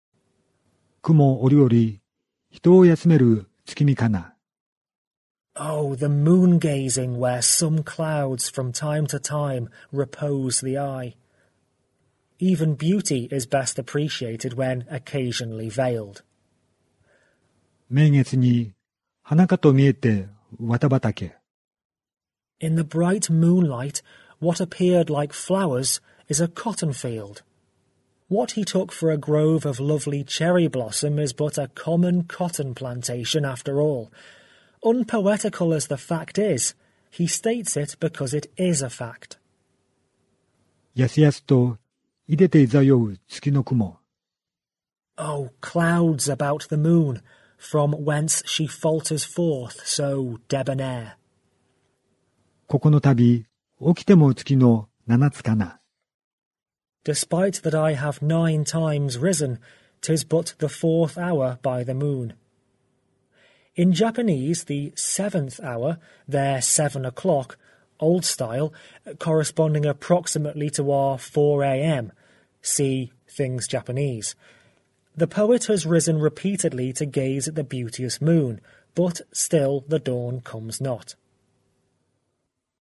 basho_haiku_moon_lib.mp3